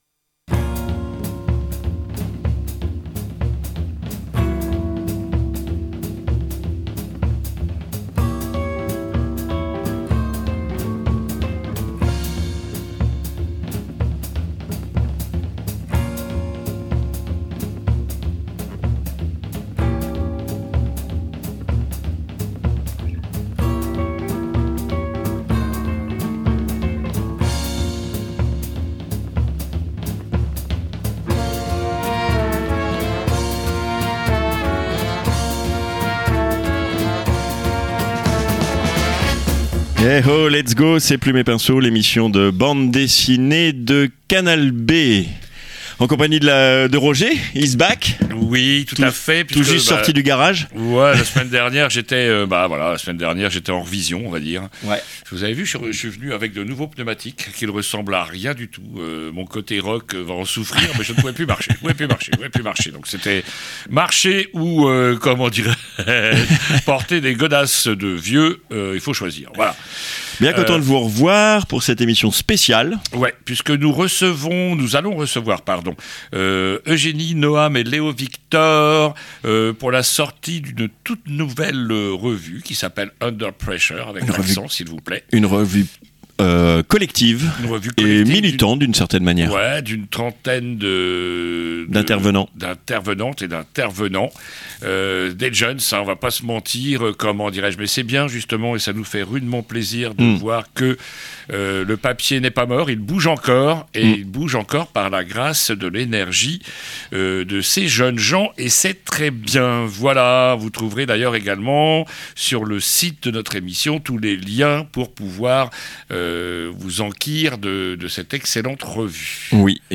528 - un Zoom avec la Revue "Underpressure Ctrl Z" 15/04/2026 60 mn I - News Jacques Armand Cardon, dessinateur du Canard enchaîné , est mort à 89 ans II - Interview ( via Zoom) UNDER PRESSURE Ctrl Z lance son premier numéro !